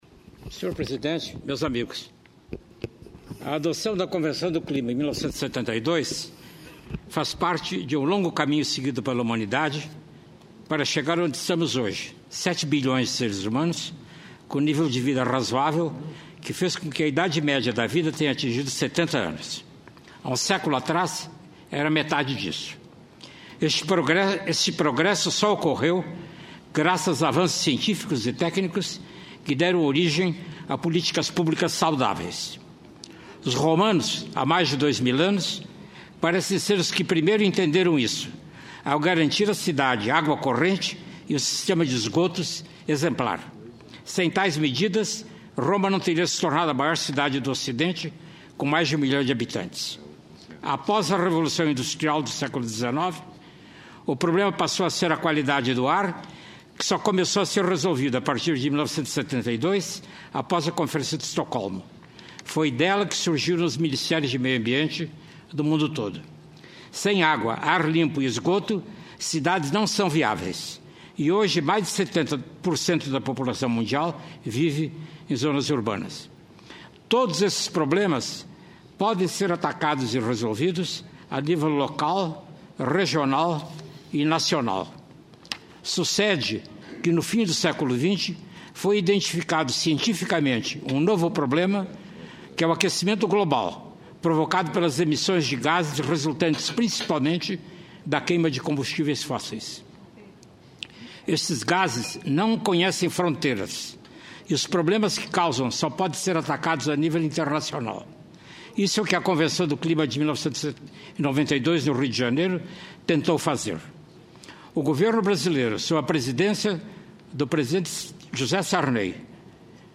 Sessão temática para debater os 25 anos da Eco 92 e da Convenção do Clima
Pronunciamento do José Goldemberg, professor e ex-ministro do Meio Ambiente